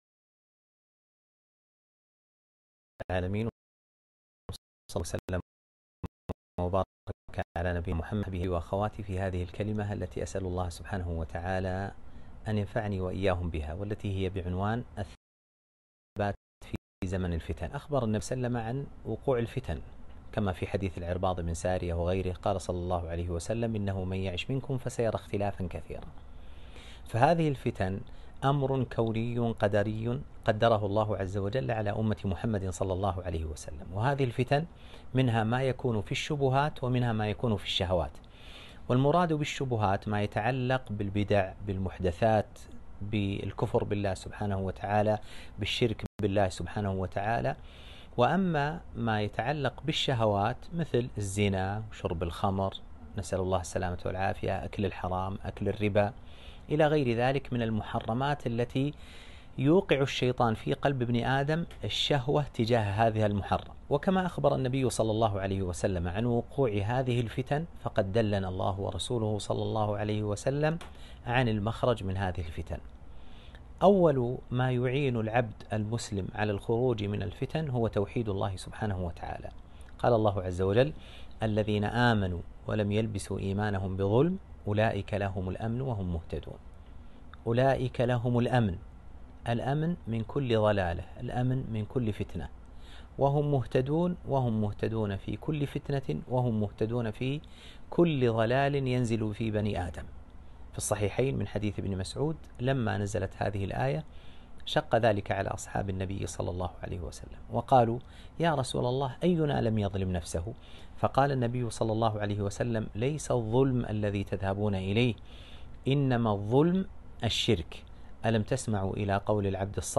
كلمة - الثبات في زمن الفتن